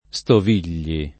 stoviglie [Stov&l’l’e] s. f. pl. — non com. il sing.: la stoviglia semplice e fiorita [la Stov&l’l’a S%mpli©e e ffLor&ta] (Gozzano) — ant. stovigli [